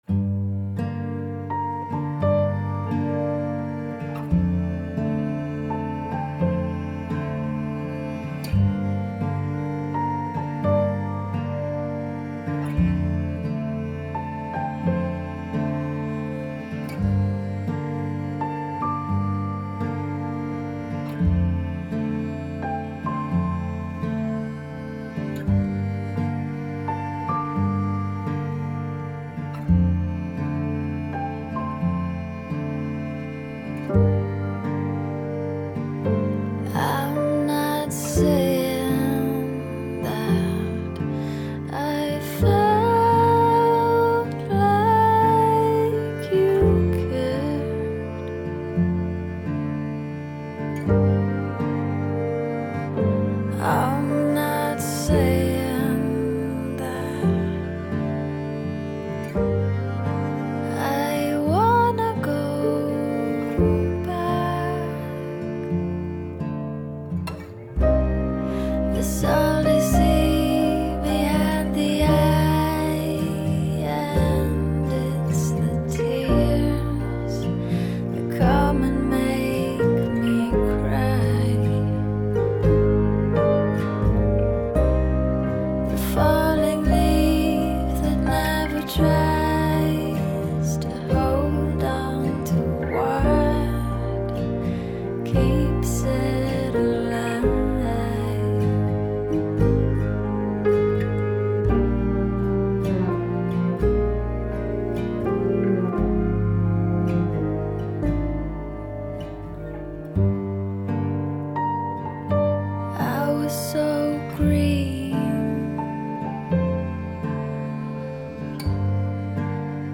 mélancolique